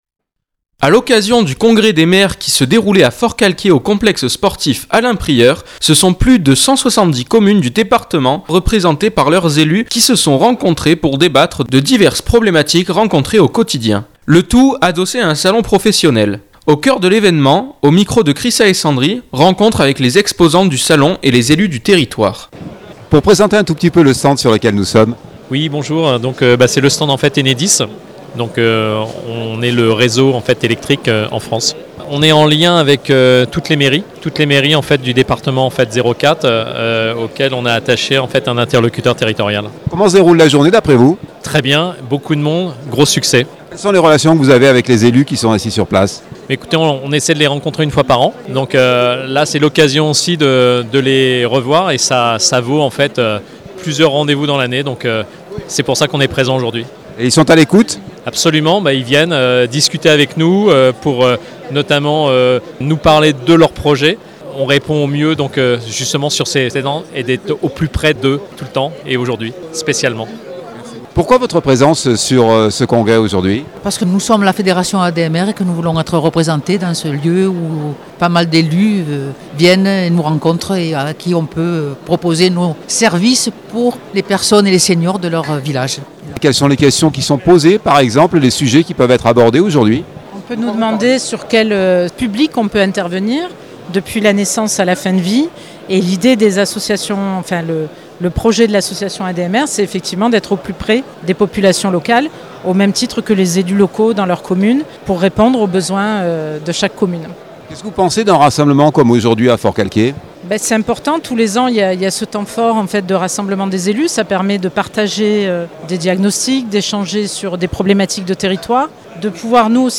rencontre avec les exposants du salons et les élus du territoire